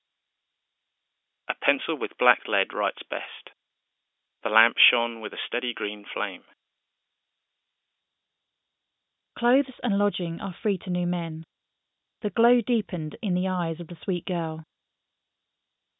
1-two-speakers-en.wav